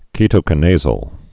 (kētō-kə-nāzōl)